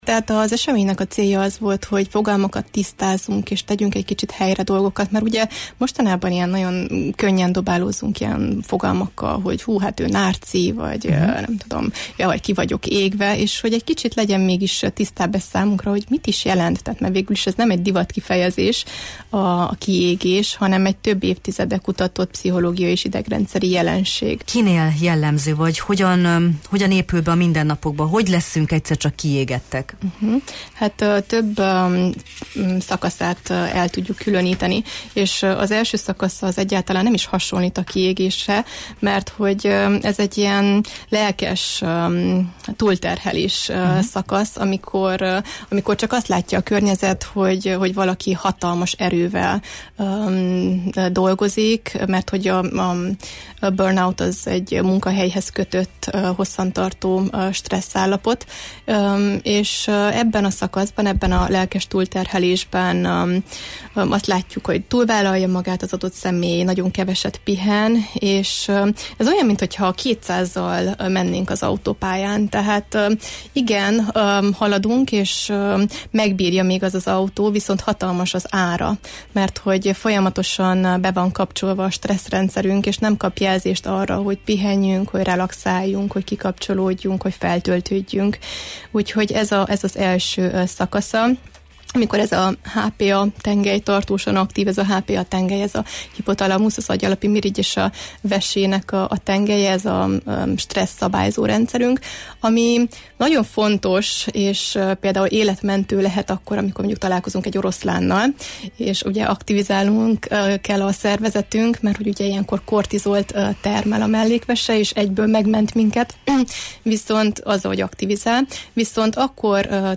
szervezet-fejlesztési coach, pszichológus volt a vendégünk a Jó reggelt, Erdély!-ben: